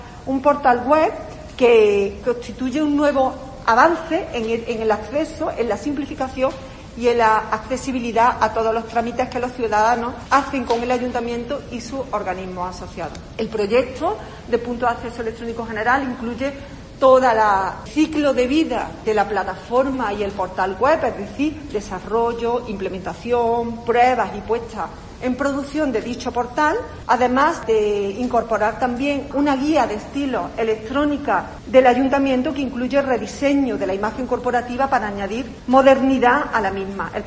En este sentido y en rueda de prensa, Morales ha resaltado que el Gobierno municipal está haciendo una "apuesta importante y clara en tecnología y en digitalización", todo ello con el objetivo de "mejorar la calidad de vida de los cordobeses en sus relaciones electrónicas con el Ayuntamiento".